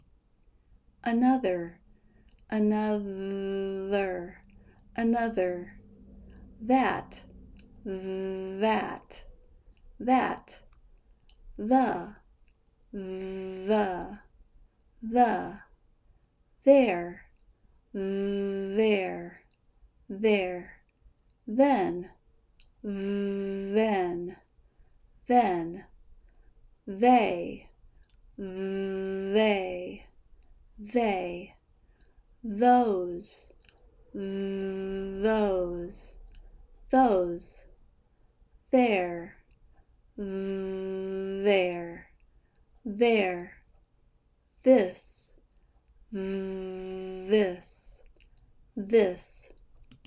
Pronounce TH in American English
Pronounce Voiced TH
Practice these words with voiced TH
voiced-th.mp3